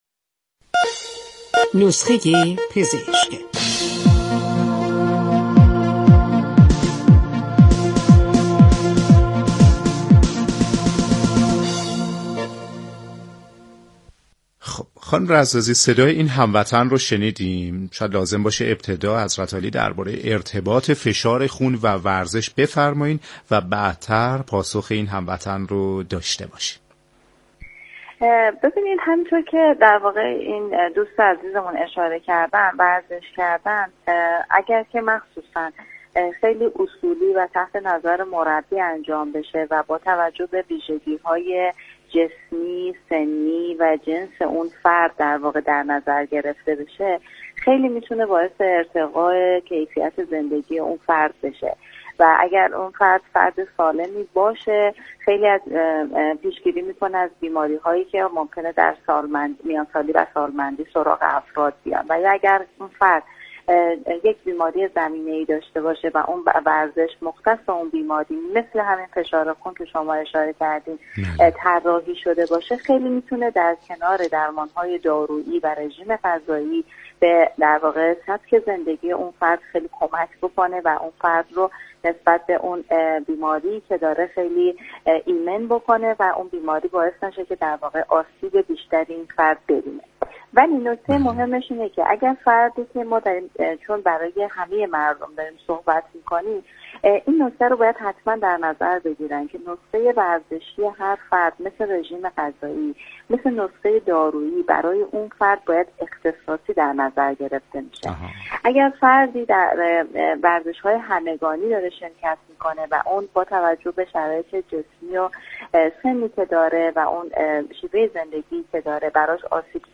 در گفت و گو با برنامه نسخه ورزشی رادیو ورزش